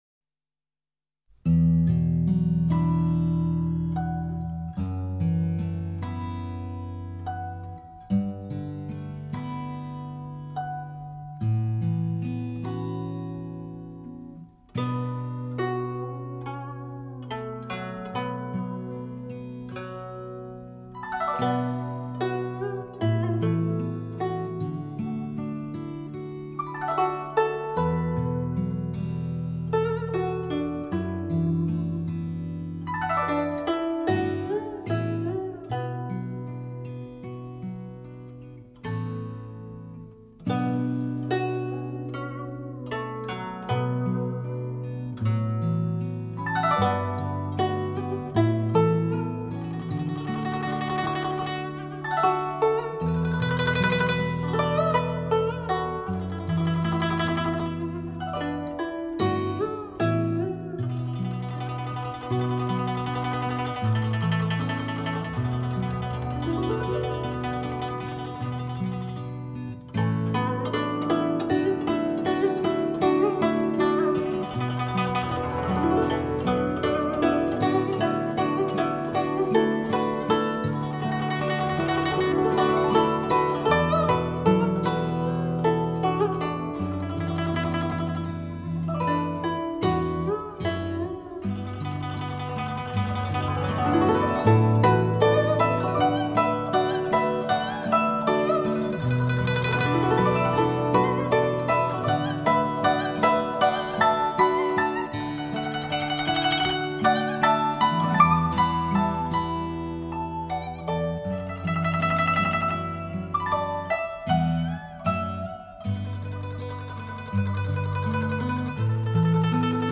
琴弦被或轻或缓或急的拨动，
音乐自然、毫无雕琢的流淌出来，像潺潺清泉。
古筝+吉他